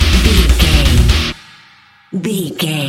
Aeolian/Minor
E♭
drums
electric guitar
bass guitar
hard rock
aggressive
energetic
intense
nu metal
alternative metal